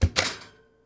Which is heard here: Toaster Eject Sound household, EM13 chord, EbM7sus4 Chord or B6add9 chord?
Toaster Eject Sound household